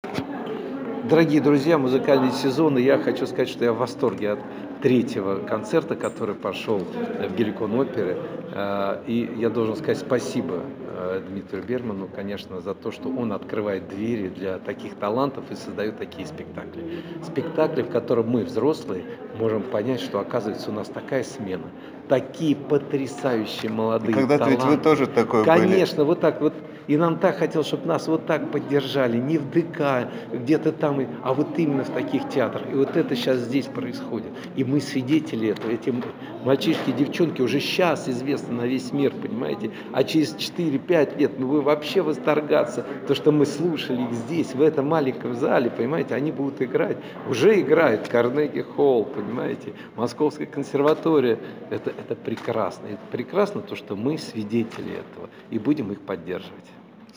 А вот что сказал специально для посетителей нашего сайта выдающийся танцовщик, заслуженный деятель искусств России, создатель и руководитель театра «Имперский русский балет» Гедиминас Таранда: